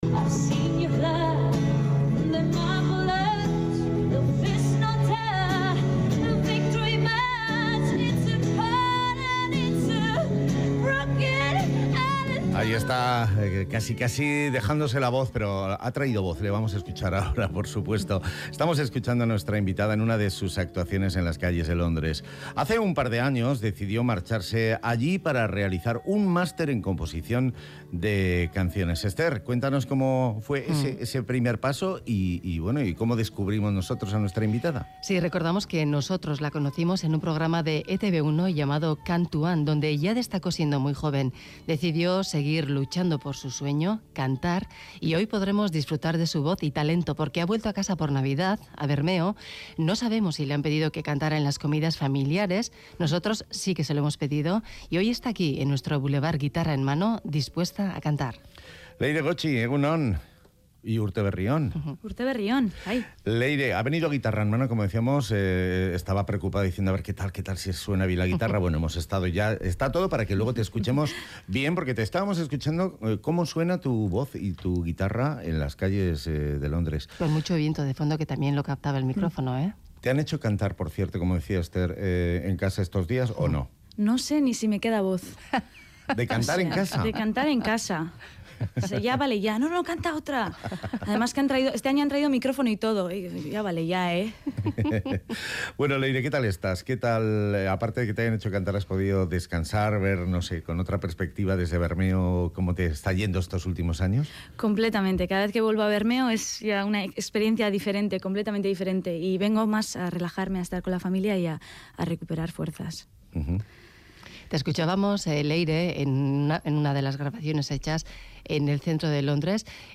Acaba de concluir su máster en composición de canciones, prepara nuevo disco, y mientras disfruta cantando en Londres. Hoy canta para nosotros.